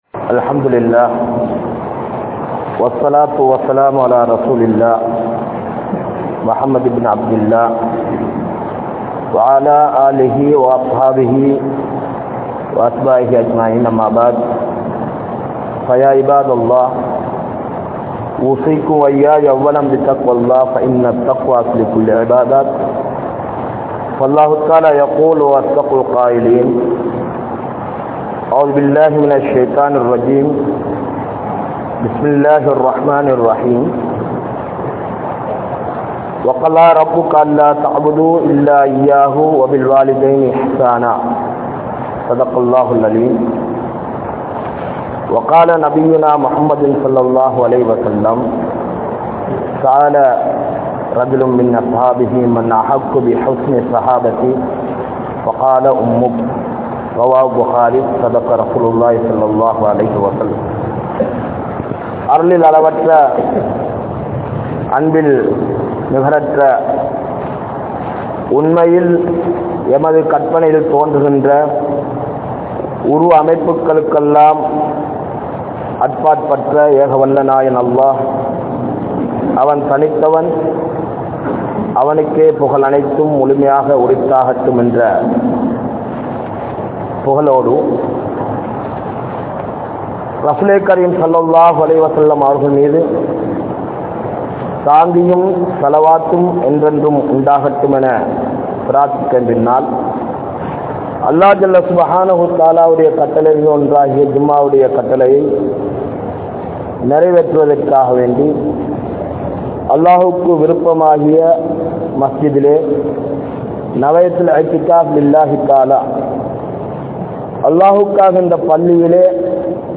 Pettroarhalin Sirappuhal (பெற்றோர்களின் சிறப்புகள்) | Audio Bayans | All Ceylon Muslim Youth Community | Addalaichenai
Grand Jumua Masjidh(Markaz)